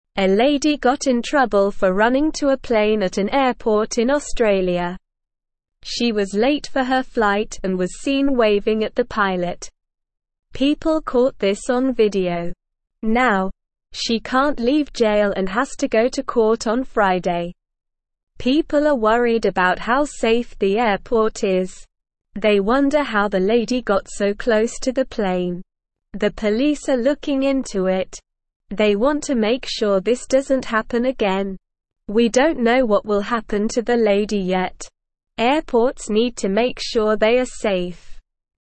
Slow
English-Newsroom-Beginner-SLOW-Reading-Trouble-at-Airport-Lady-Runs-to-Plane.mp3